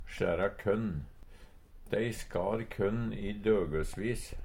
sjæra kønn hauste korn Infinitiv Presens Preteritum Perfektum sjæra sjær skar skøre Eksempel på bruk Dei skar kønn i døgøsvis. Høyr på uttala Ordklasse: Verb Kategori: Jordbruk og seterbruk Attende til søk